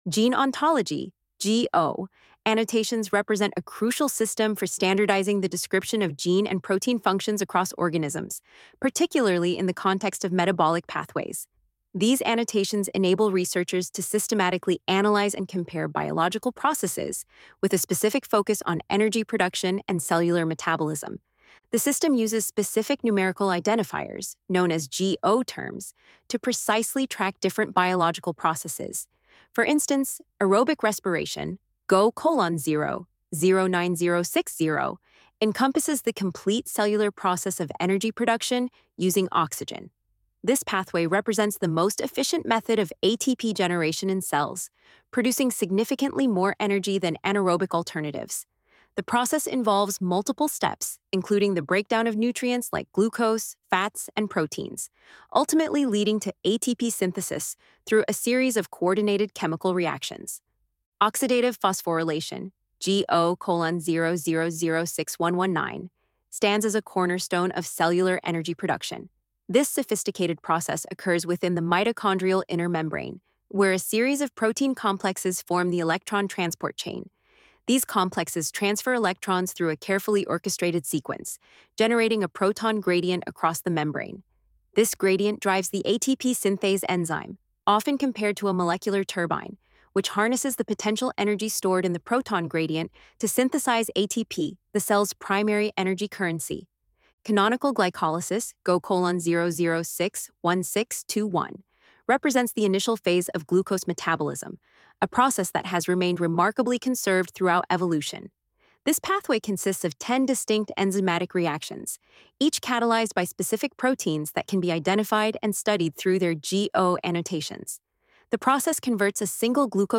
ElevenLabs_Decoding_Gene_Ontology_A_Metabolic_Pathway_Overview.mp3